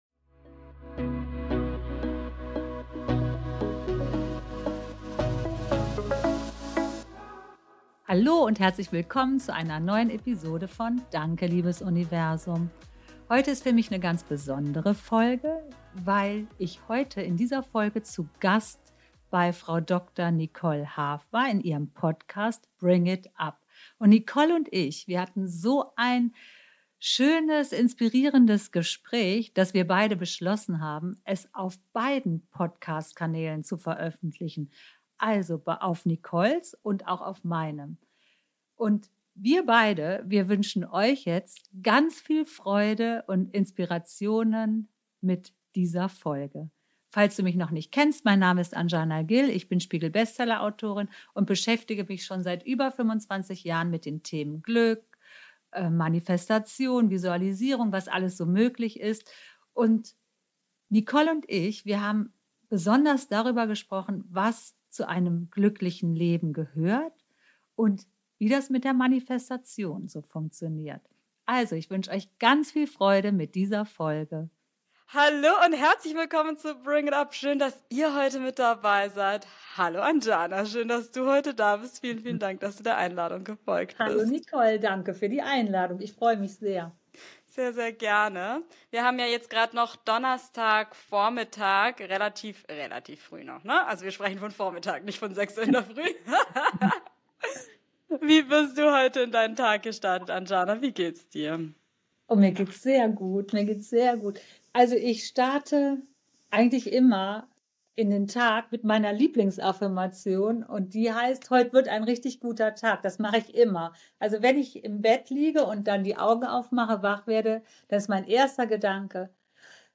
Ein Gespräch über magische Alltagstricks, kraftvolle Routinen und die größte Beziehung deines Lebens: ...